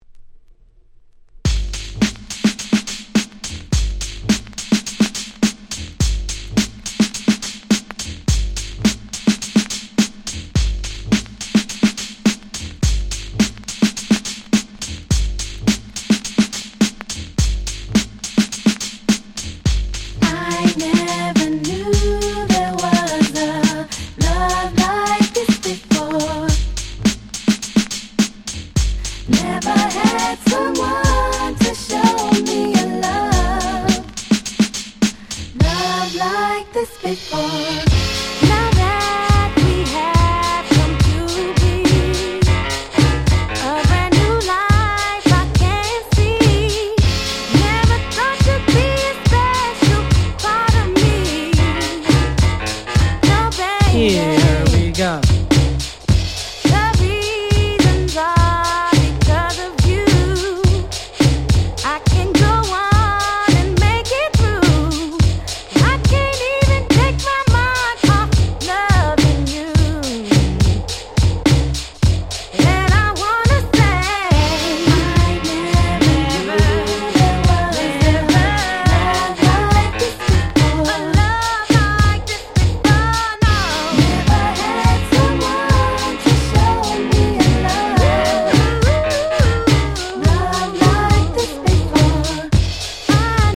DJ用にイントロにBreakがついて繋ぎ易くなってたりするアレです。